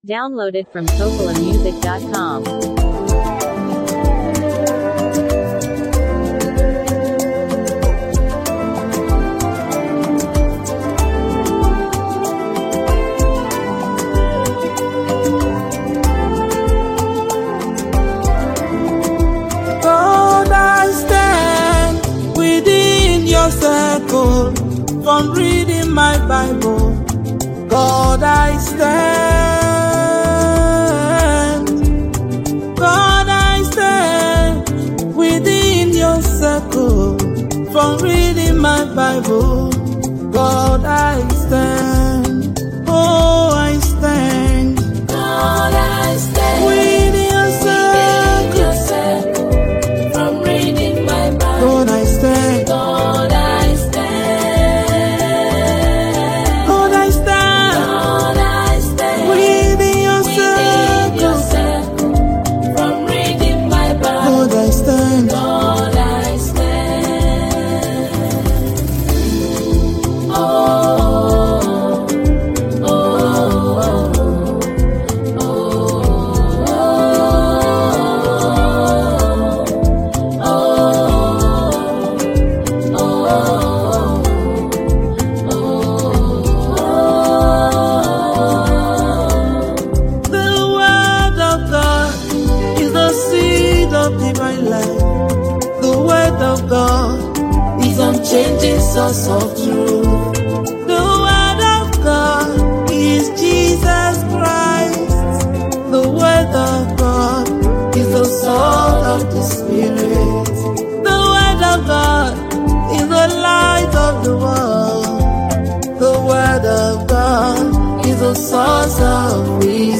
powerful gospel song